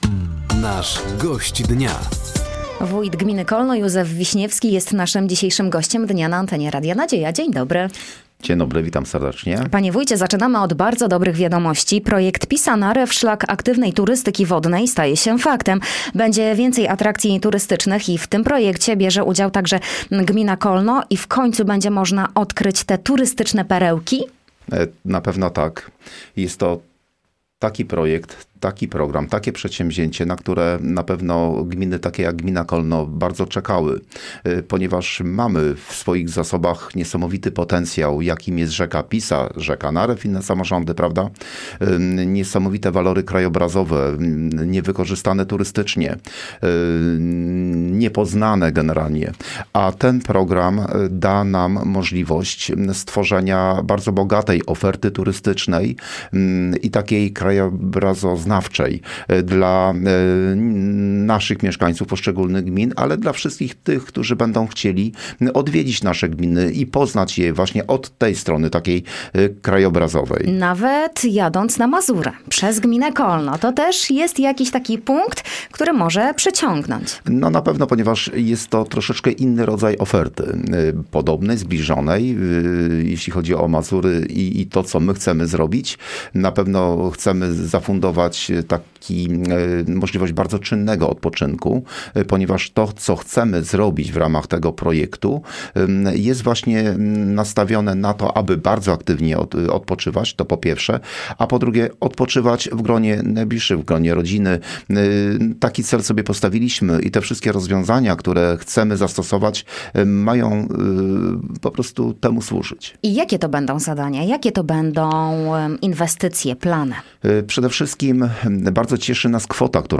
Realizacja projektu ,,Pisa-Narew – szlak aktywnej turystyki wodnej” na terenie gminy Kolno – to główny temat rozmowy z Gościem Dnia Radia Nadzieja.